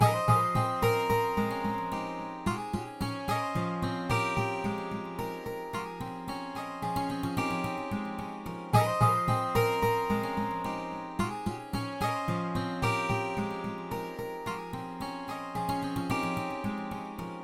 吉他键 110
Tag: 110 bpm Trap Loops Guitar Acoustic Loops 2.94 MB wav Key : F Cubase